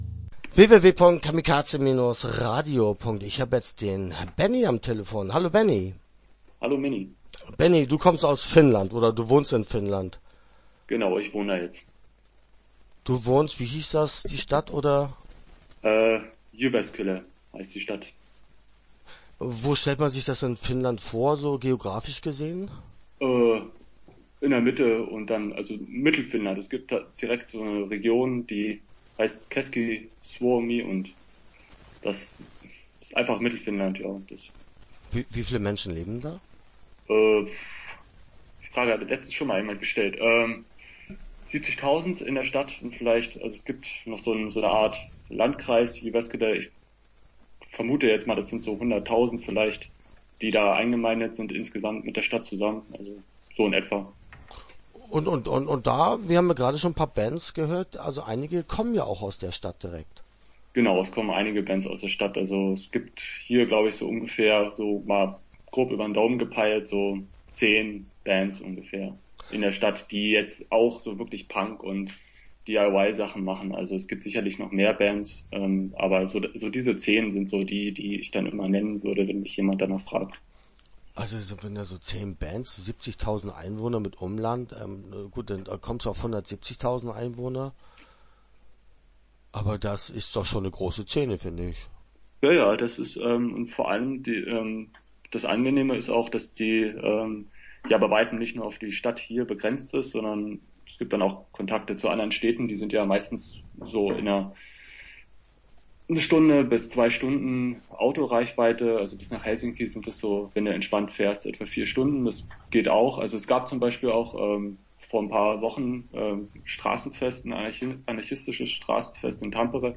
Punk in Finnland - Interview Teil 1 (12:46)